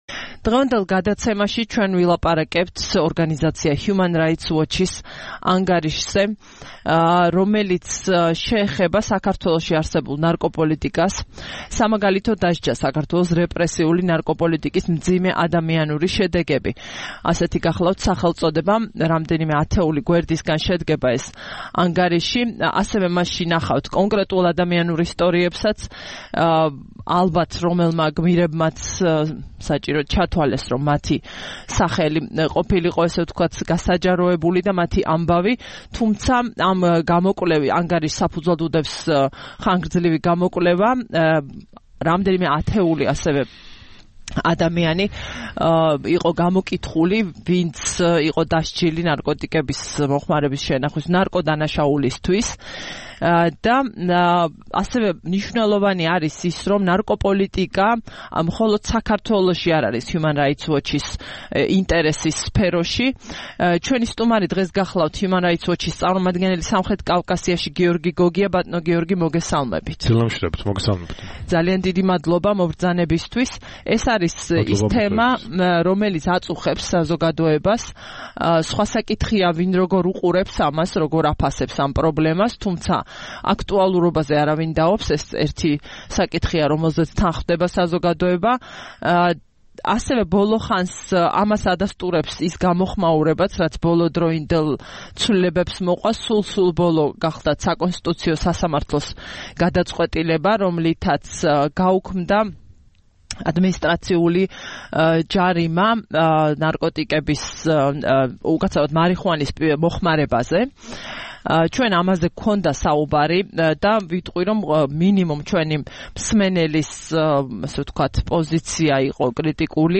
რადიო თავისუფლების "დილის საუბრების" სტუმარი